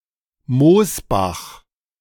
Mosbach (German pronunciation: [ˈmoːsˌbax]
De-Mosbach.ogg.mp3